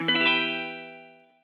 DDW Guitar Hit.wav